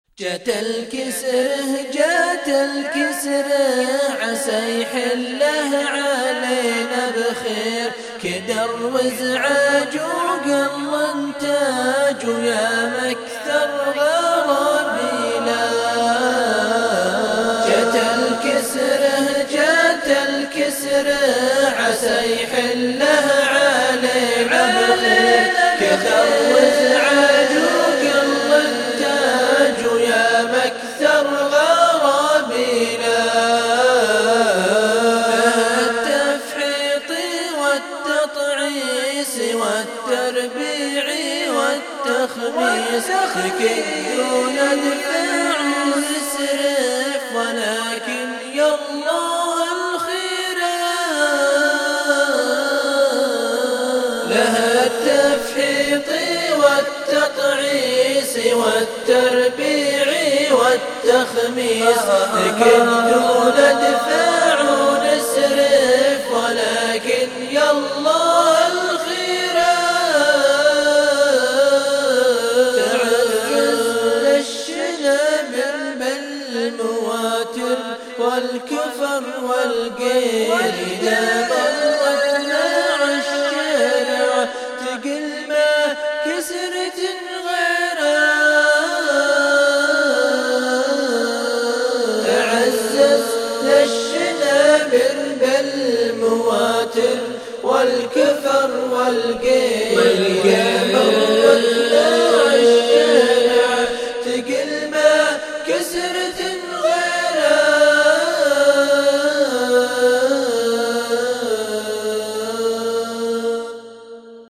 مير الشلعة اللي بالثانية 25 خياااااااااااااالــ ــ ــ !